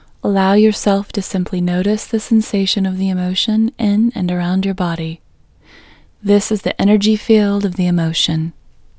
OUT Technique Female English 3